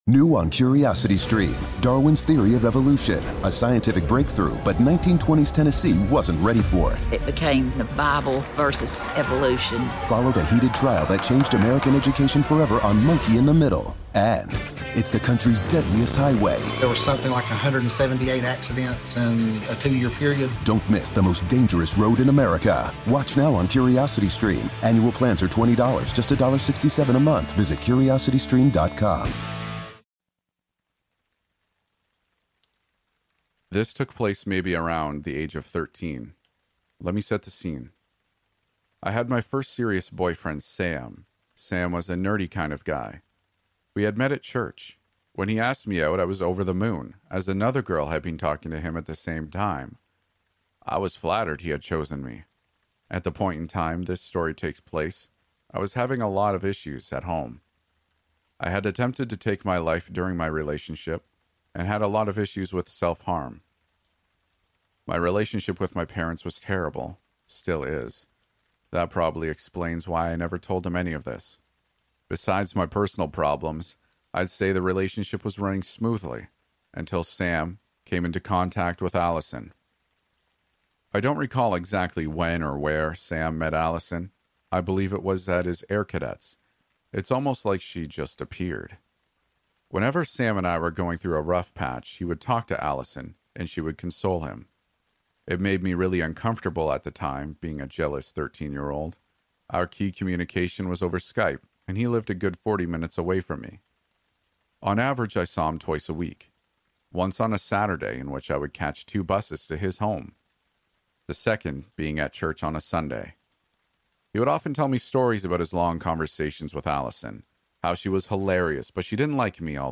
Episode 24 | Seven True Stalker Stories | Storytelling